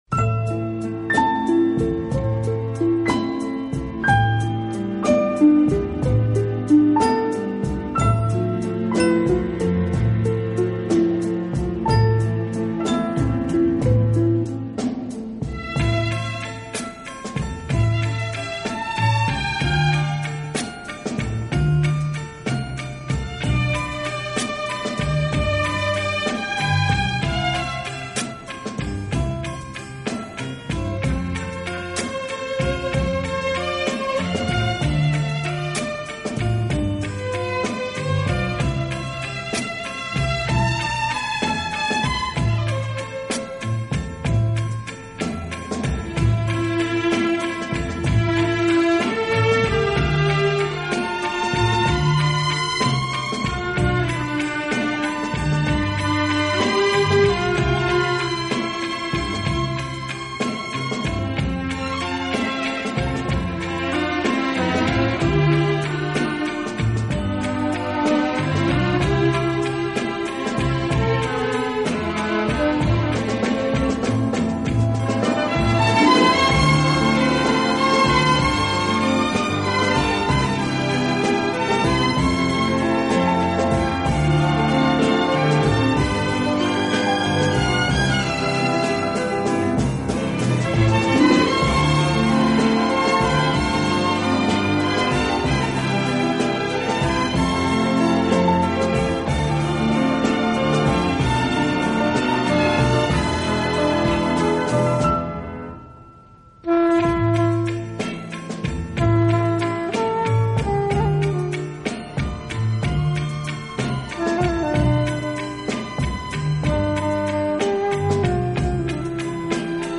其风格清新明朗，华丽纯朴，从不过分夸张。